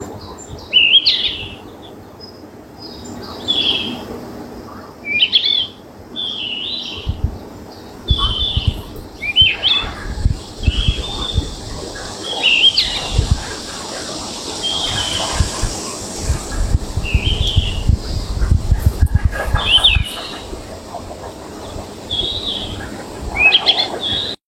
ringtone ave 9